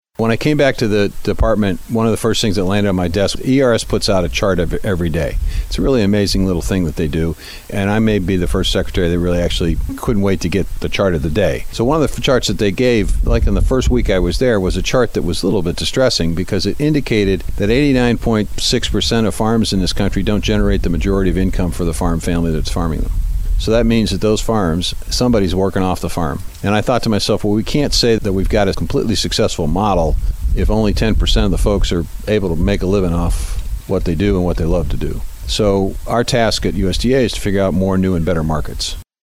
US Agriculture Secretary Tom Vilsack says it’s something he noticed when he returned to USDA with the Biden administration.